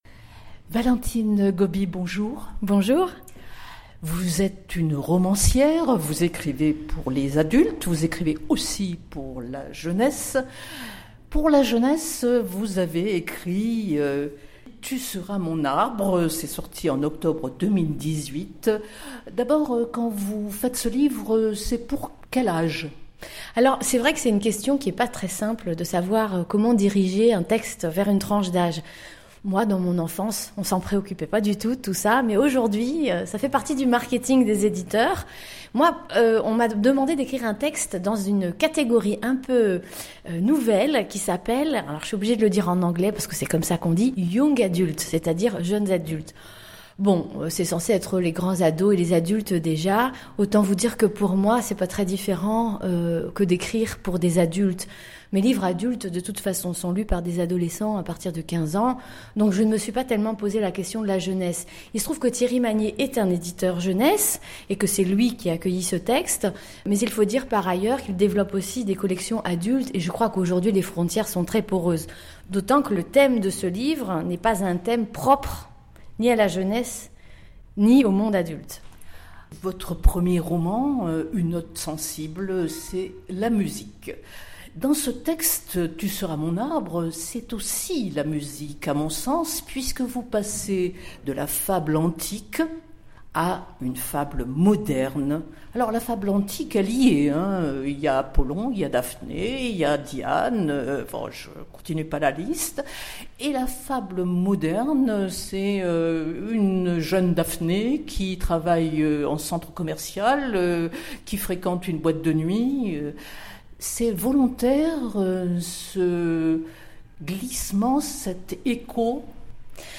valentine_goby_itw_19_01_19.mp3